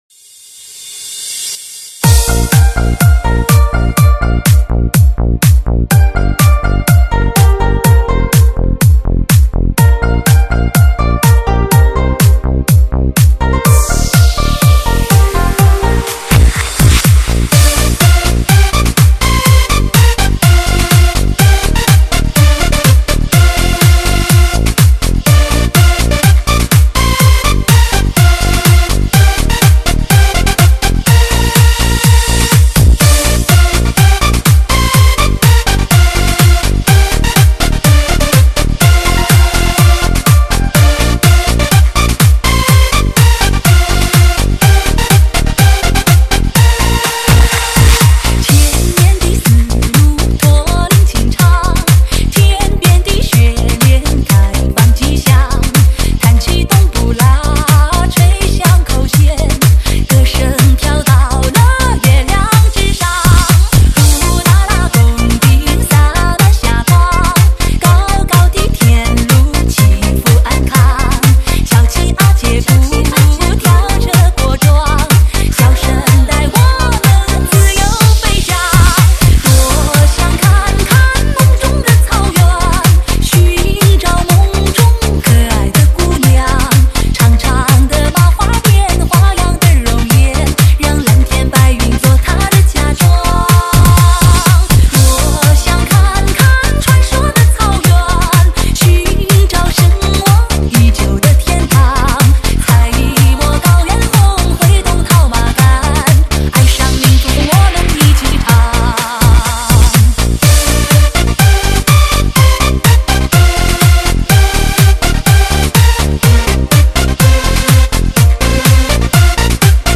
慢摇
THE BEST DISCO 草原风情摇滚
至尊震撼的流行慢摇王中王 让每一
拍音乐律动都POWERFUL